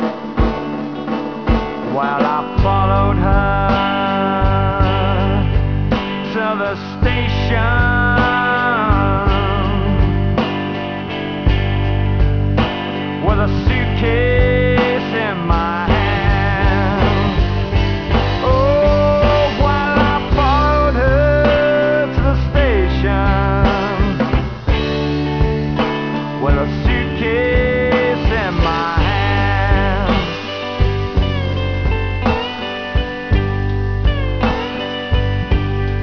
All samples are 8bit 11KHz mono recordings
Rhythm & Blues experience you'll never forget